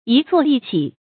一坐一起 yī zuò yī qǐ
一坐一起发音